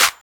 [ACD] - TrapMusic Snare (2).wav